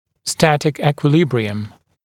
[‘stætɪk ˌiːkwɪ’lɪbrɪəm] [ˌekwɪ’-][‘стэтик ˌи:куи’либриэм] [ˌэкуи’-]статическое равновесие